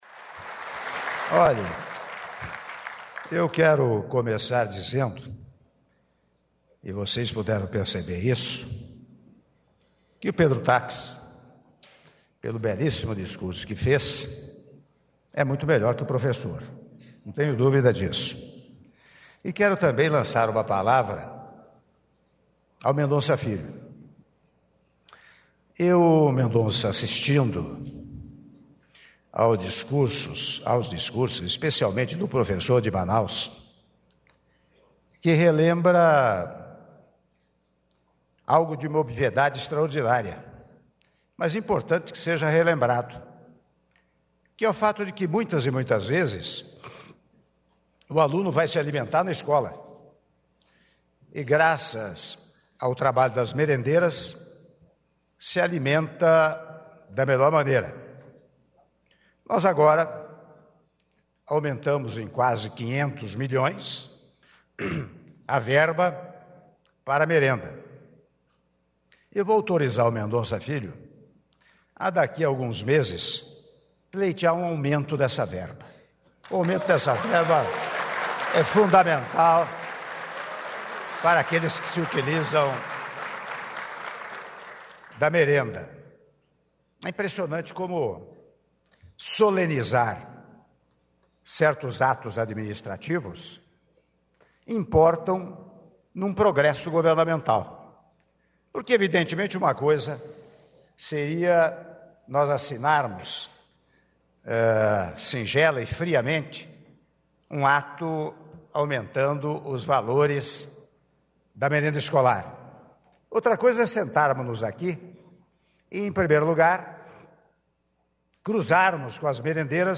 Áudio do discurso do Presidente da República, Michel Temer, durante cerimônia de anúncio de recursos para merenda escolar - Palácio do Planalto (07min17s)